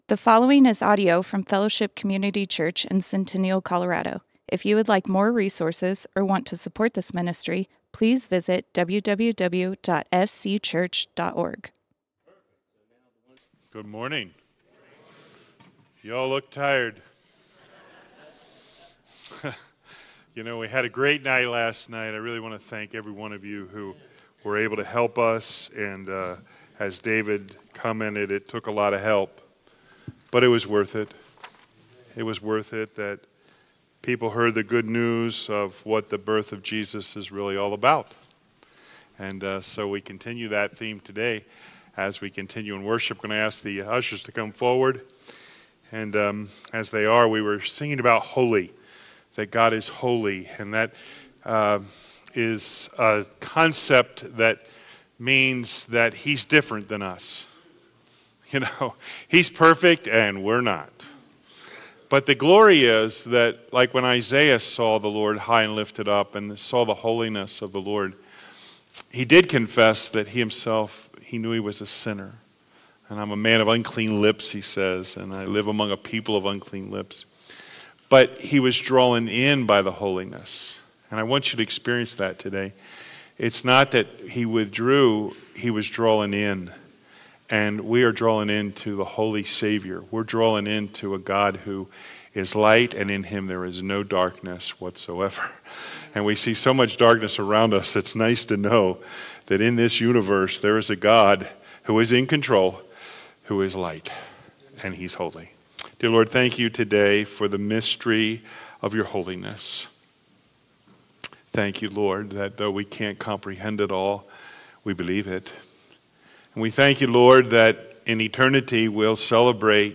Fellowship Community Church - Sermons Why Shepherds?